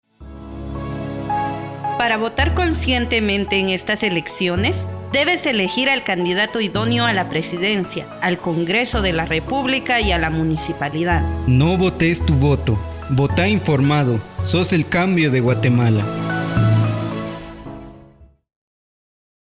Spots Radial